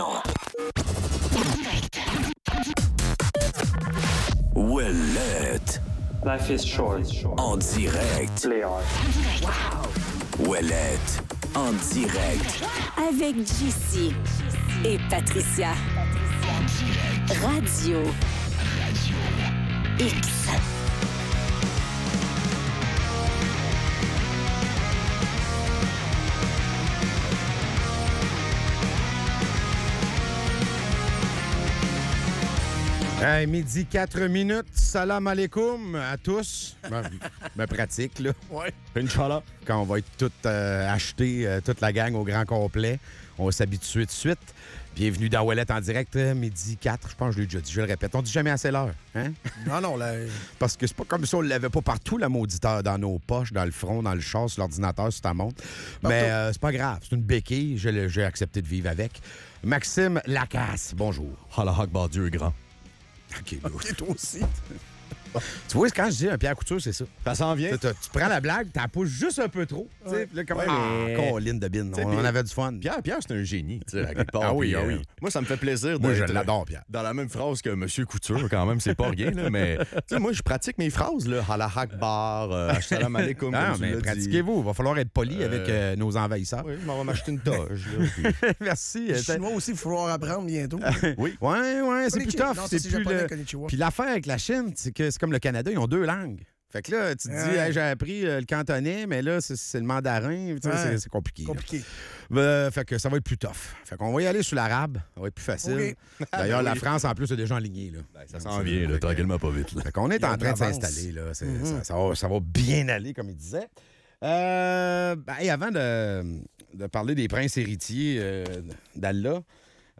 La fermeture de 19 patinoires extérieures à Québec suscite la controverse, les animateurs critiquant cette décision face à l'importance des loisirs d'hiver pour les jeunes. Ils soulignent l'ironie de remplacer des patinoires abordables par des infrastructures coûteuses et discutent des enjeux budgétaires qui en découlent.